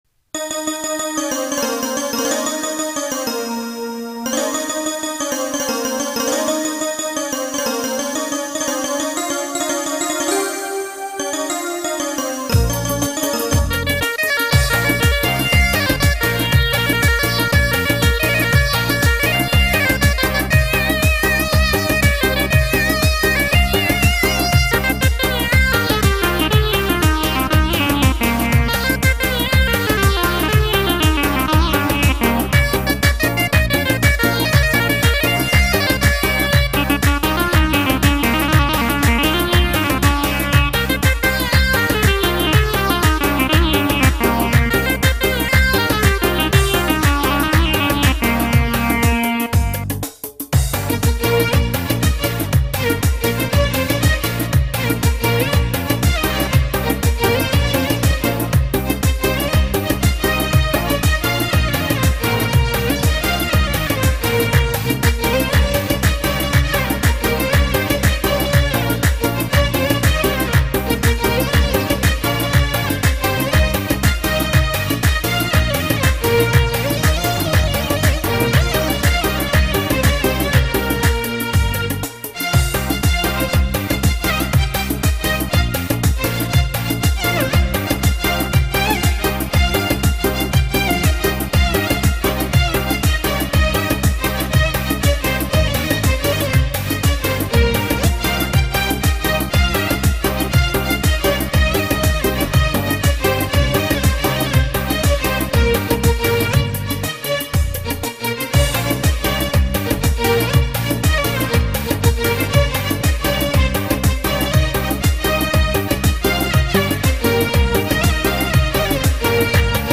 ریمیکس شاد بندری بی کلام ارکستری